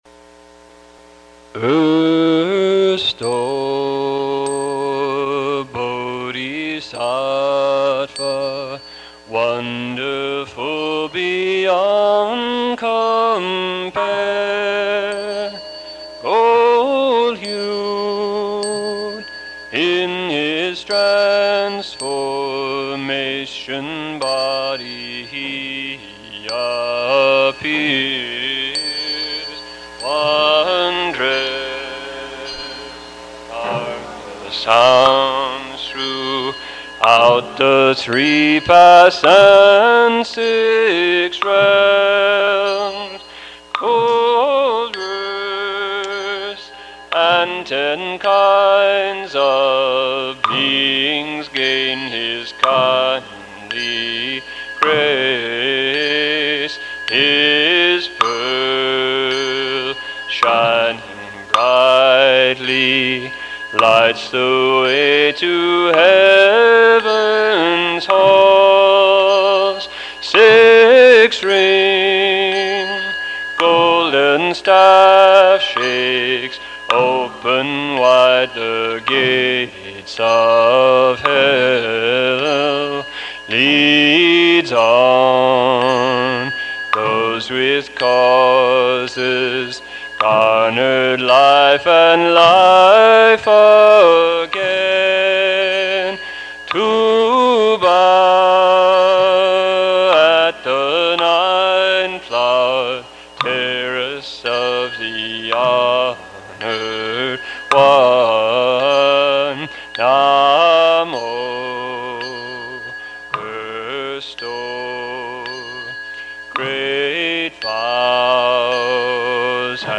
English Songs: